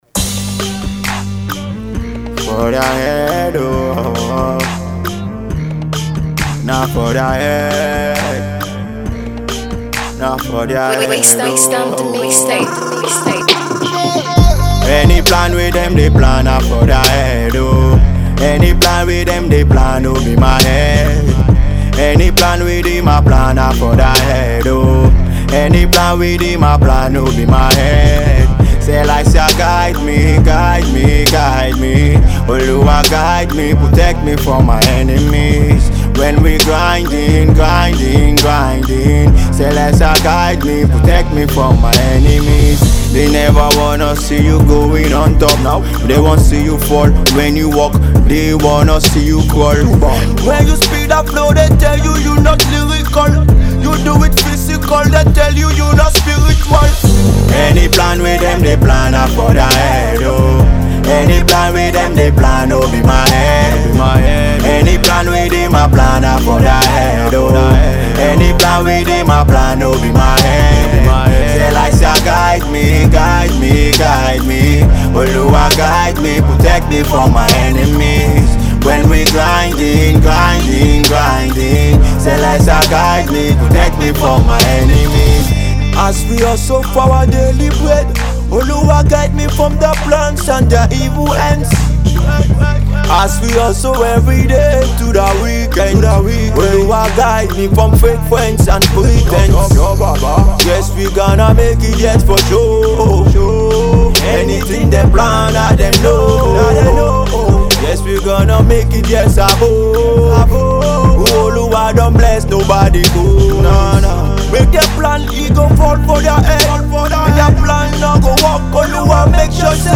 Alternative Rap